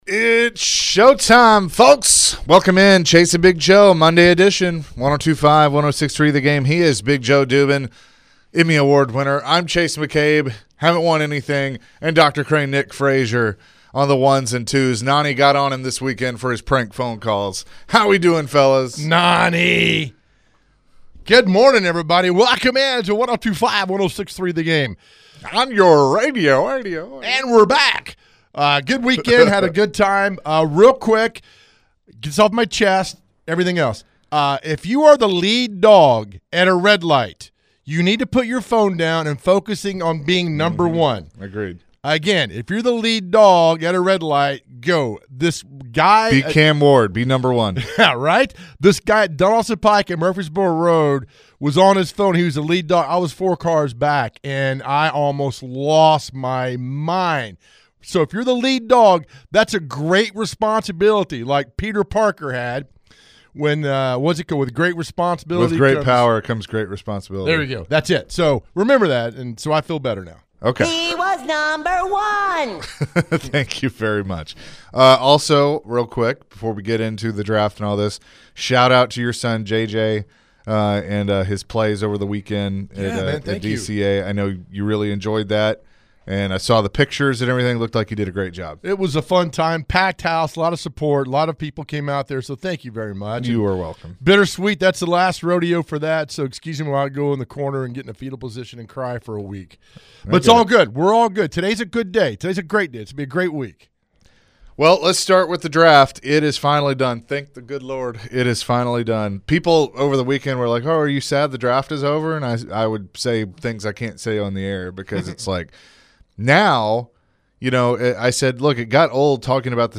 To end the hour, the guys answered some calls and texts pertaining to the Titans!